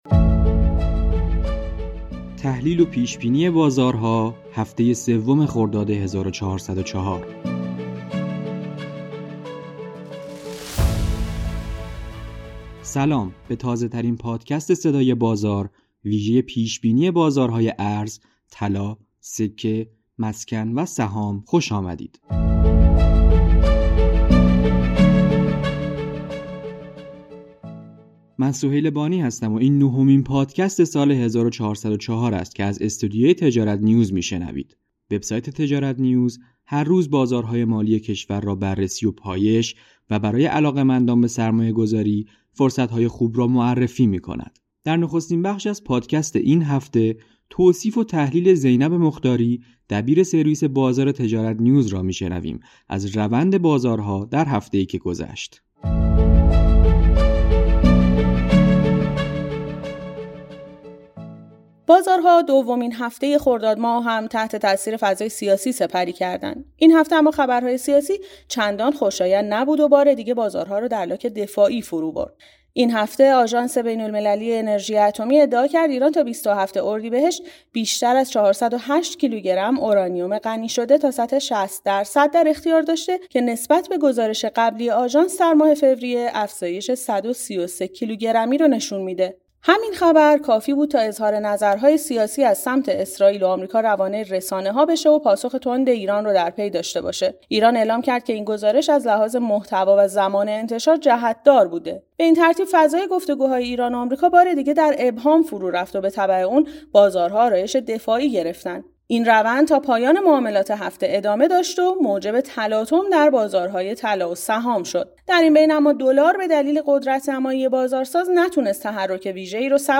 به تازه‌ترین پادکست صدای بازار ویژه پیش بینی بازارهای ارز، طلا، سکه، مسکن و سهام خوش آمدید. این نهمین پادکست سال 1404 است که از استودیوی تجارت‌نیوز می‌شنوید.